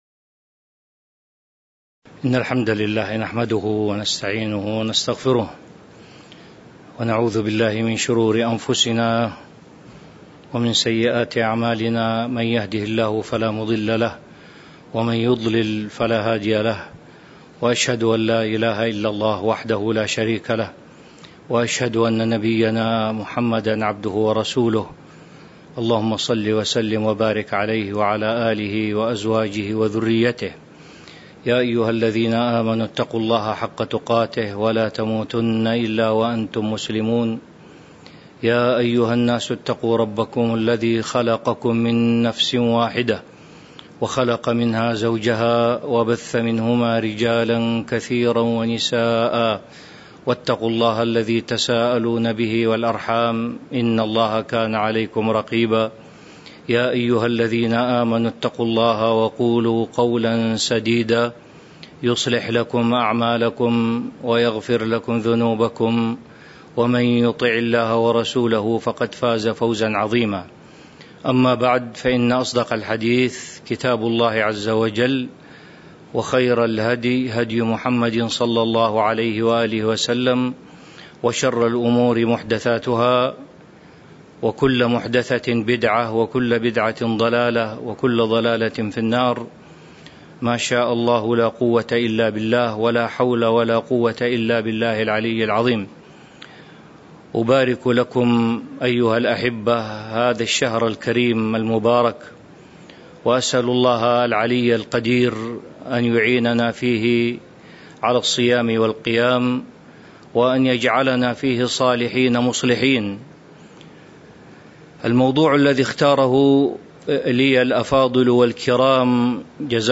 تاريخ النشر ٧ رمضان ١٤٤٢ هـ المكان: المسجد النبوي الشيخ